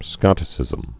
(skŏtĭ-sĭzəm)